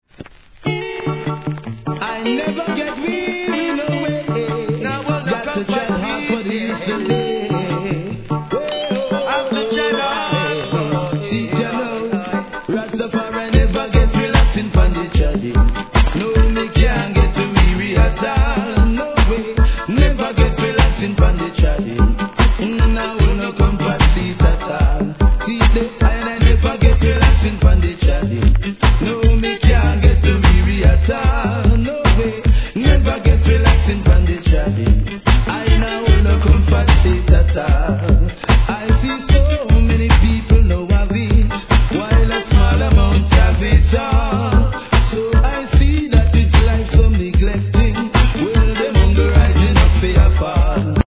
REGGAE
南国チックな気持ち良いイントロから最高、2001年の大人気、大ヒットRIDDIM!!